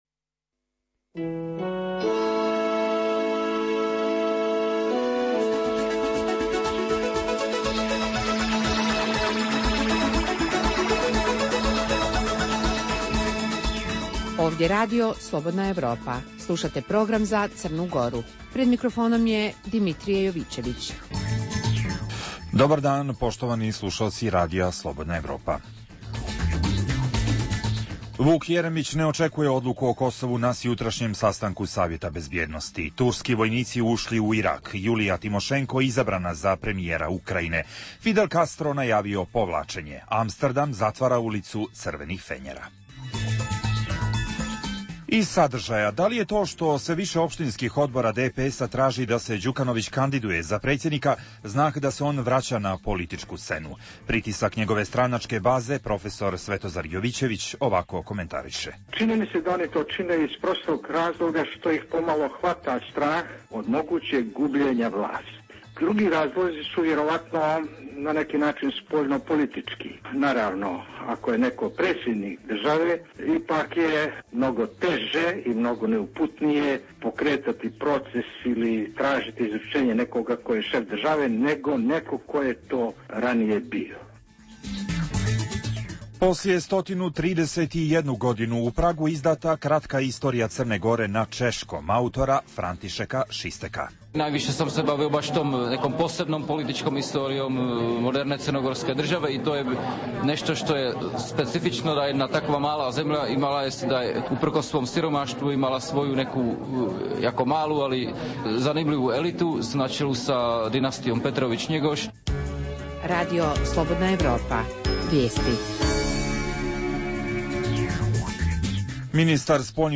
Emisija namijenjena slušaocima u Crnoj Gori. Bavimo se pitanjem da li se Đukanović vraća na političku scenu kao kandidat za predsjednika na zahtjev svoje stranačke baze?